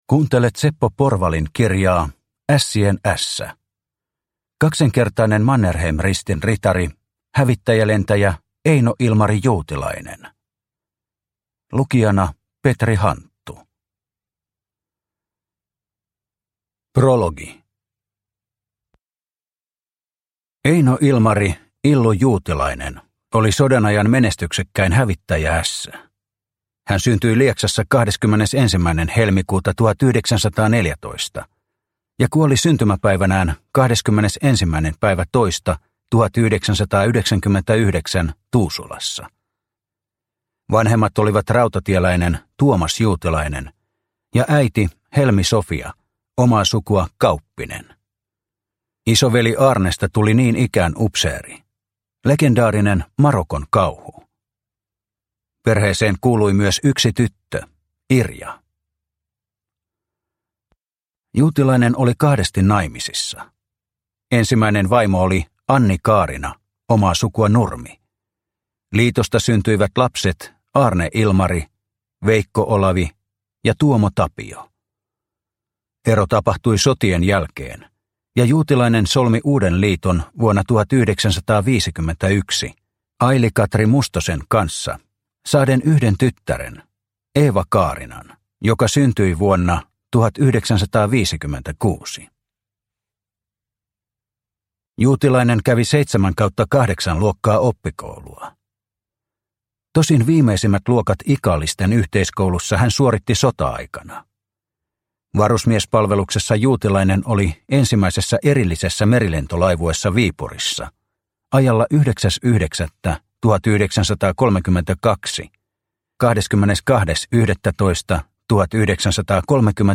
Ässien ässä – Ljudbok – Laddas ner